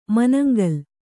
♪ manangal